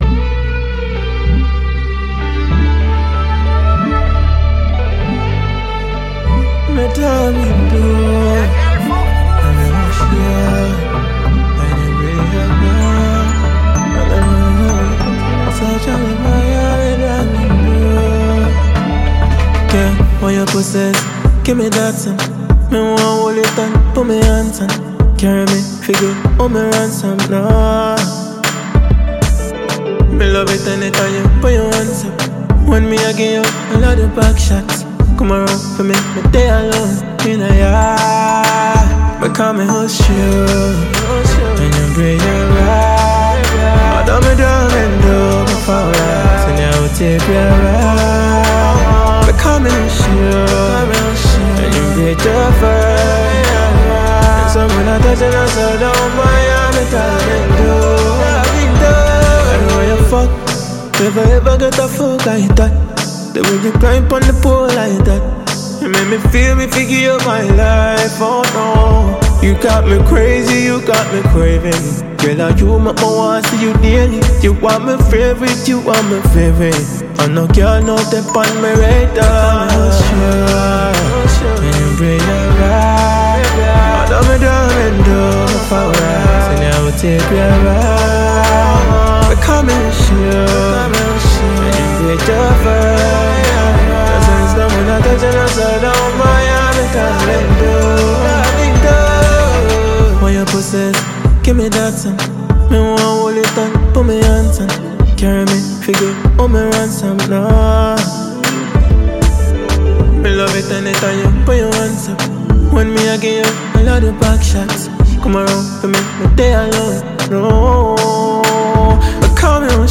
Ghanaian reggae-dancehall musician